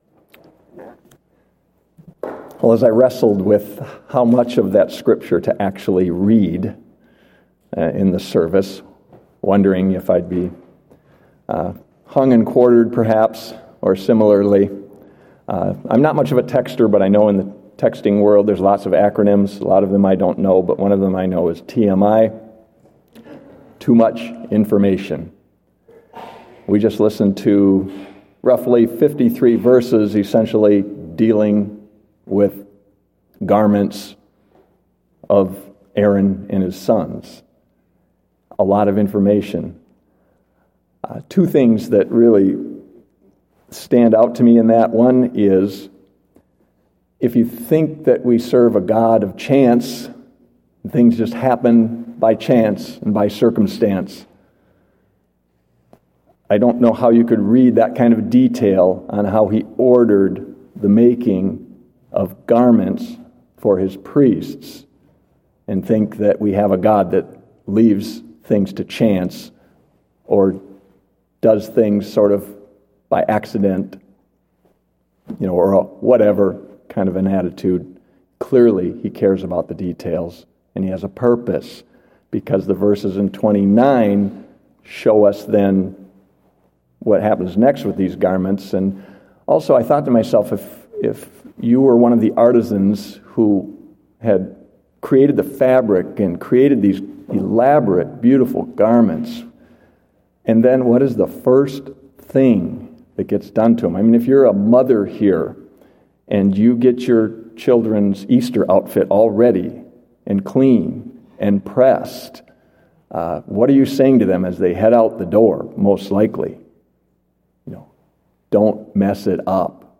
Date: March 13, 2016 (Evening Service)